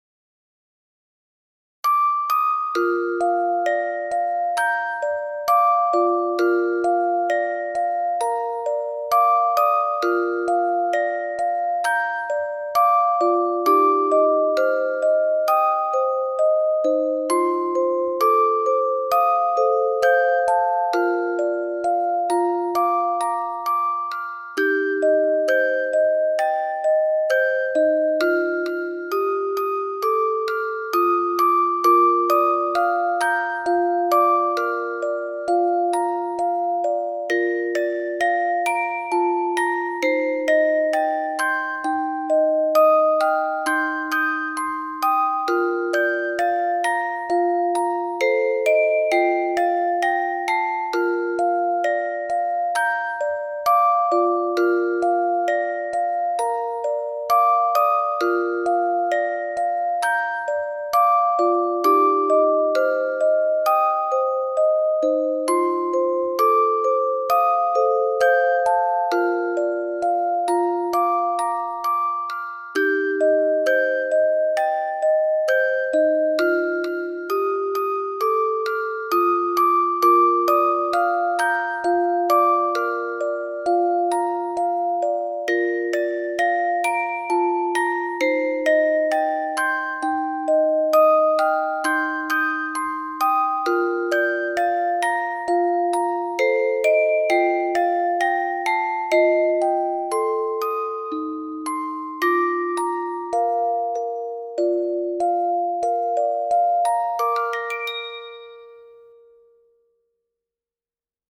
途中で拍子が変わるオルゴールです。
心に染み入る音色に惹かれました。